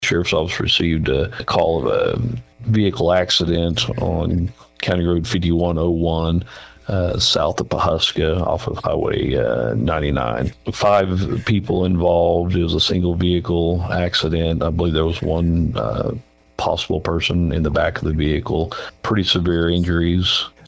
Sheriff Bart Perrier goes on to give more details.